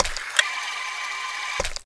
rifle_barrel_spin2.wav